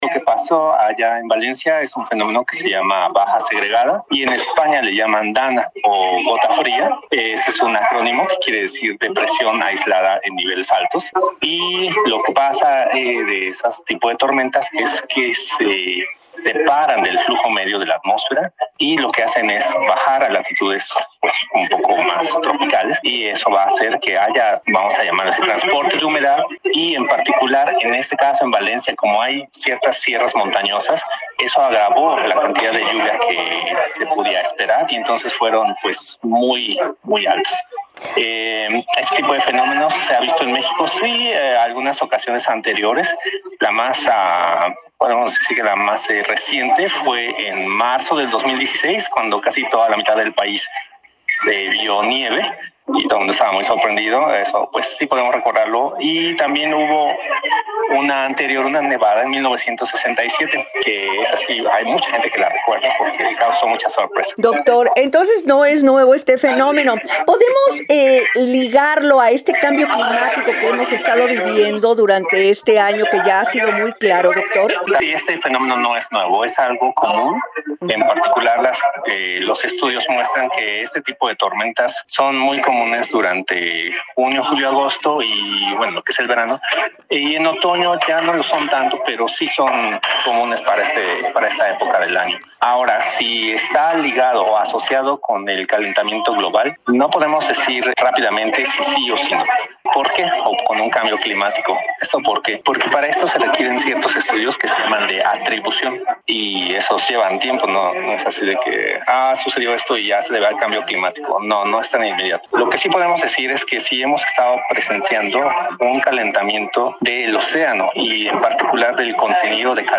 14-ENTREVISTA-CLIMA-01-NOV.mp3